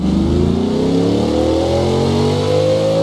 Index of /server/sound/vehicles/lwcars/chev_suburban